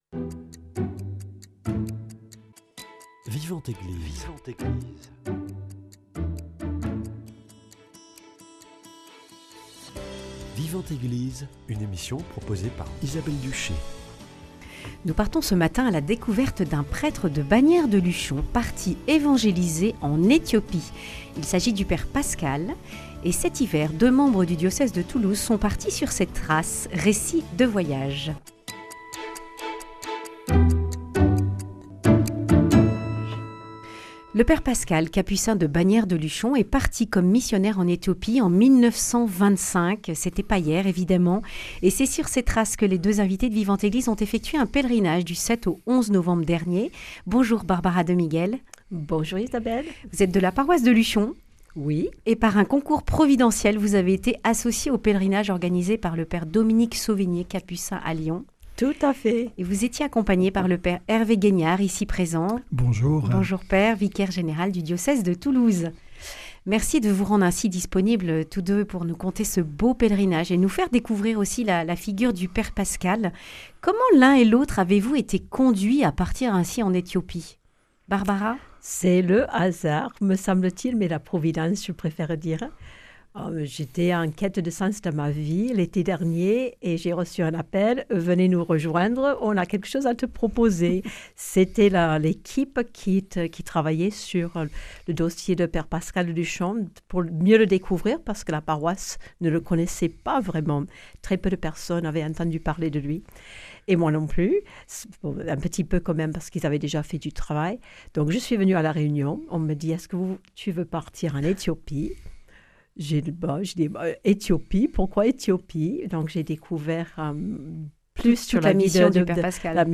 Ils témoignent de cette figure exceptionnelle.